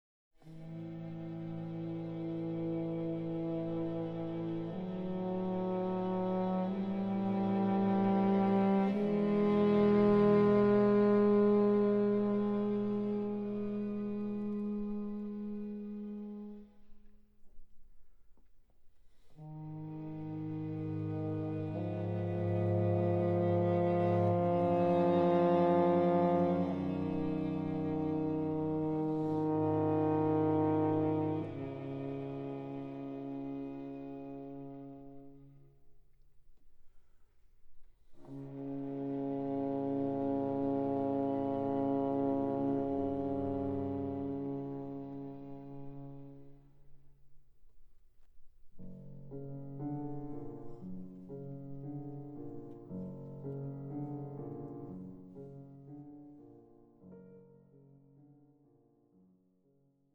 Quintet for French Horn, Violin, Viola, Cello and Piano